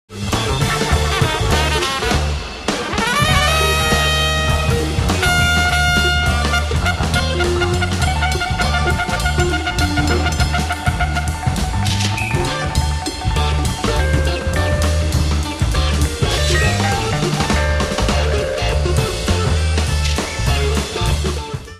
サウンドボード録音
誰が聞いてもこれがブートとは信じ難い音質でマニアも納得のノー・カット盤になります。